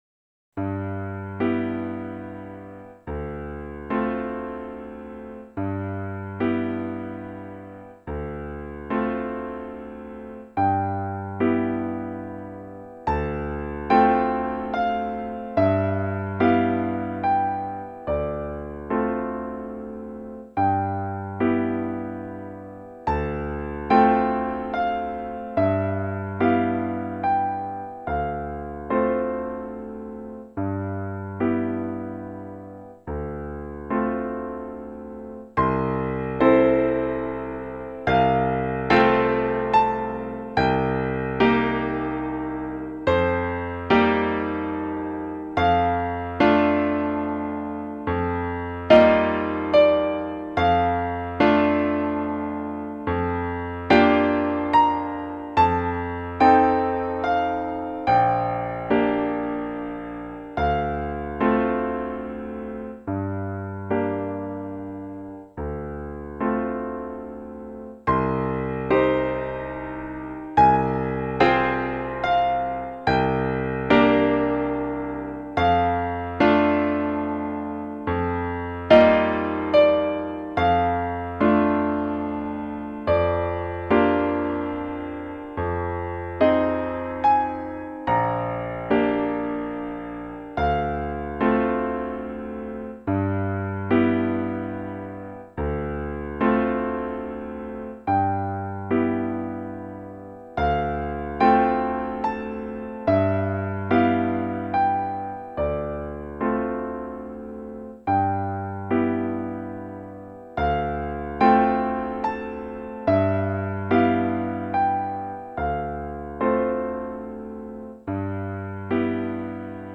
シンセサイザーによるＭＩＤＩの演奏の録音です。
◆ピアノ曲